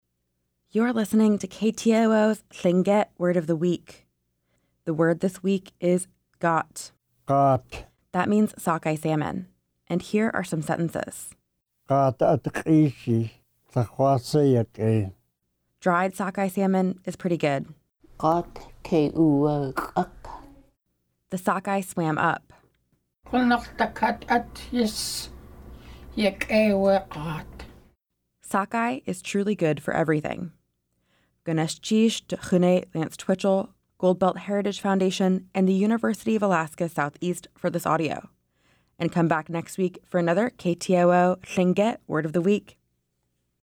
Each week, we feature a Lingít word voiced by master speakers.